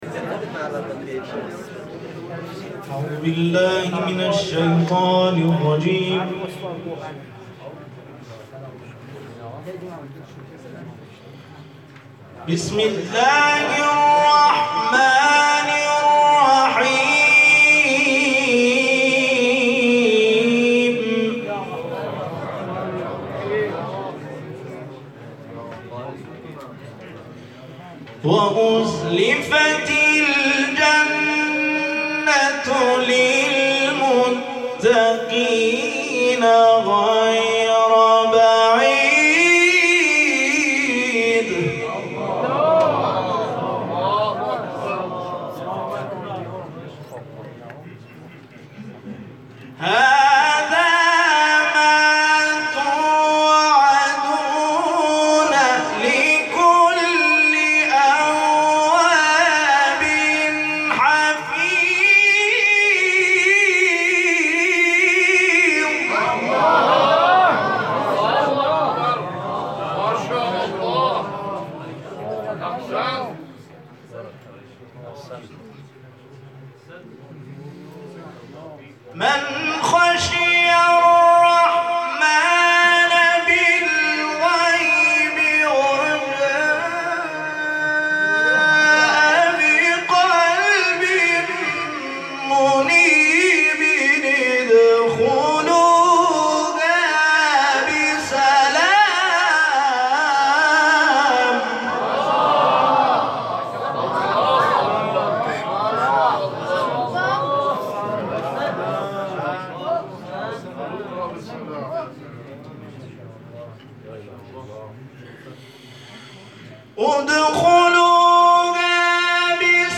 تلاوت سوره ق